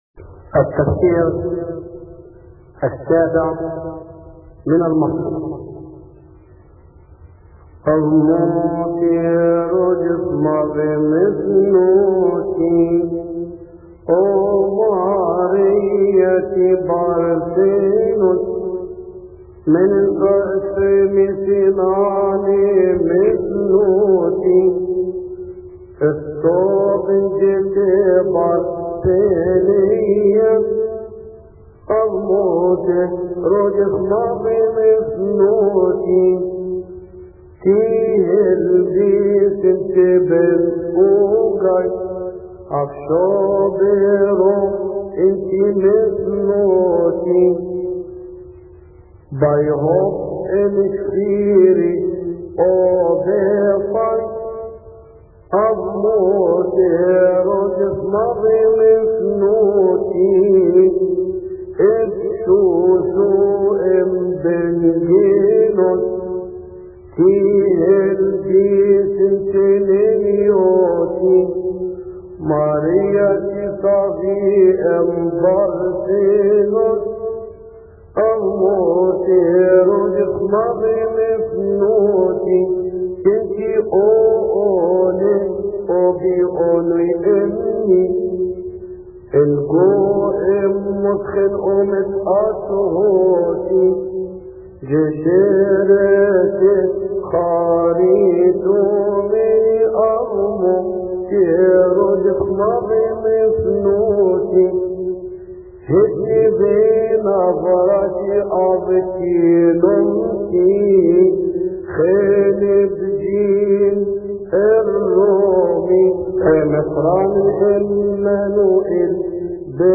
مكتبة الألحان
يصلي في تسبحة عشية أحاد شهر كيهك